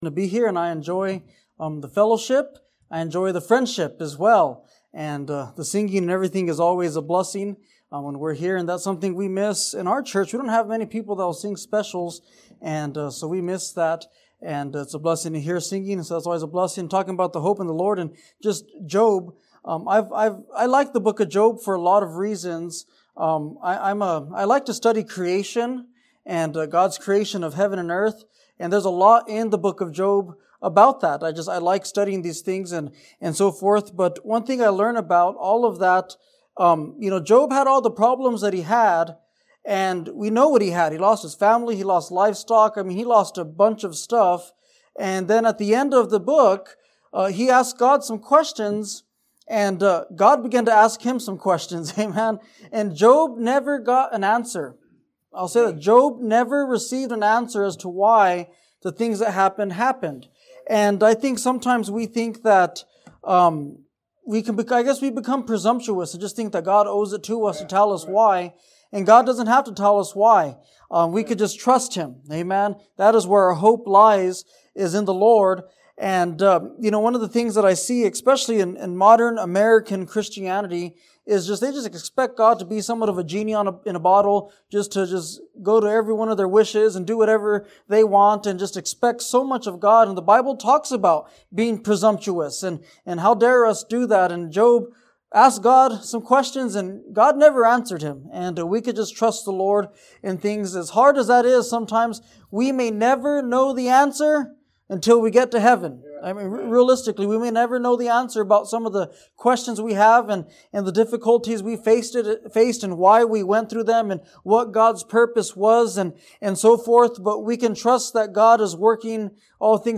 A message from the series "Stand Alone Messages." The gospel is the message of salvation that can save any sinner from their sins. Learn what it means to have your sins forgiven.